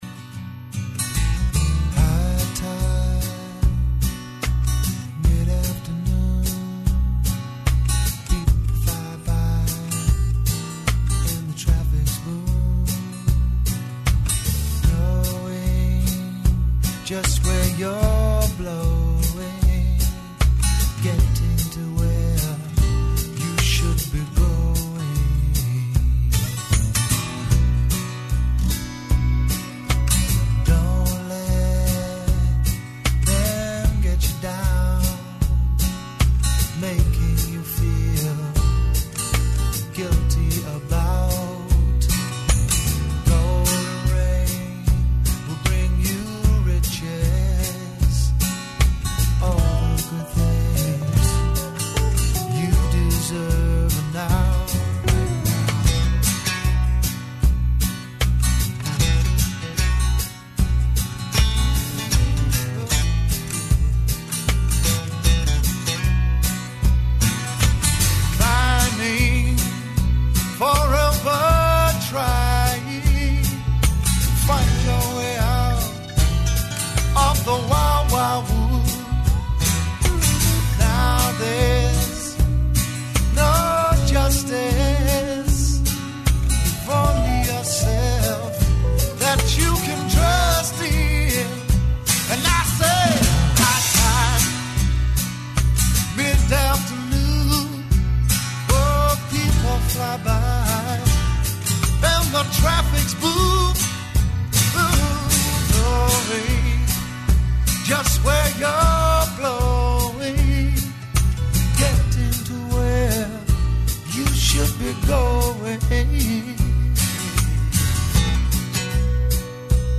Тада добијају појачање у виду живог бубња и мењају свој правац у Noise Rock.
Емисија из домена популарне културе.